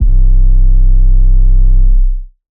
SOUTHSIDE_808_yo_dirt_C.wav